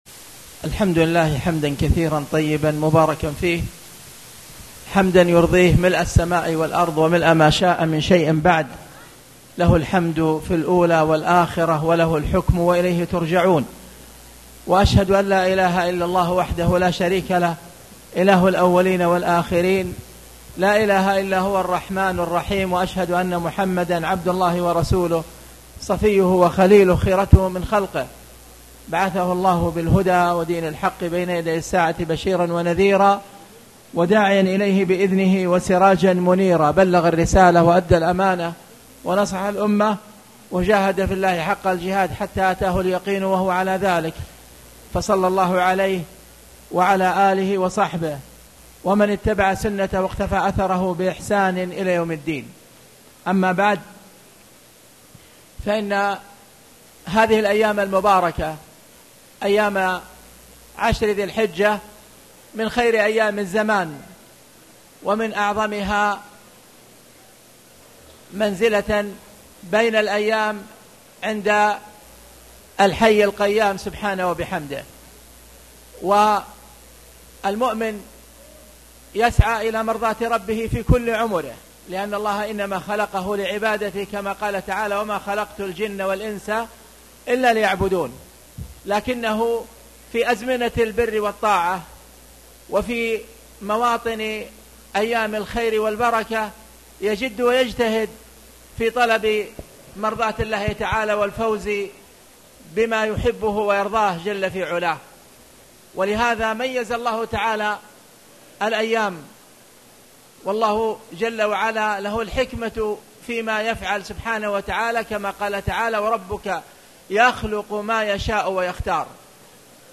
تاريخ النشر ١ ذو الحجة ١٤٣٨ هـ المكان: المسجد الحرام الشيخ